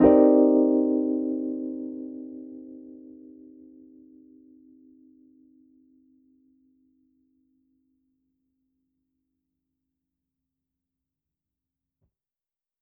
JK_ElPiano3_Chord-Cm9.wav